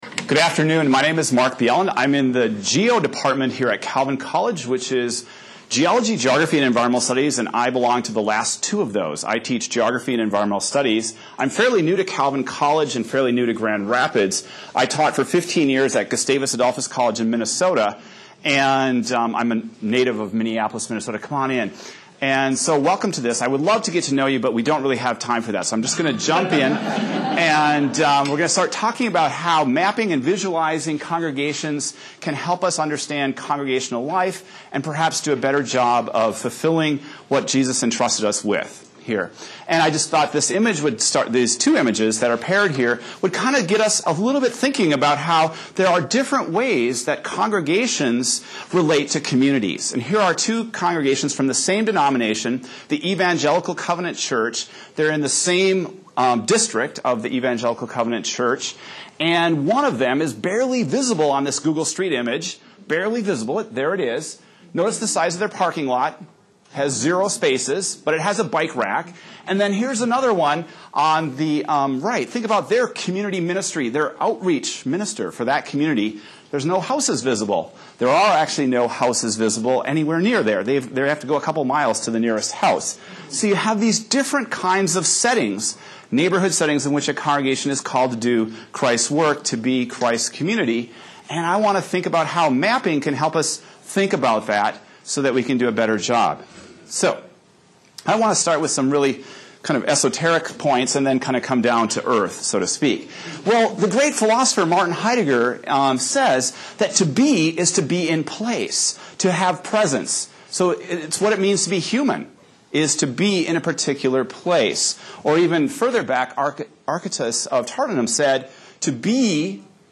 This workshop explores different mapping and visualization techniques to better understand our communities, our congregations, and relationships between them. Some of these techniques involve nothing more than pencil and paper while others involve computer programs and online resources.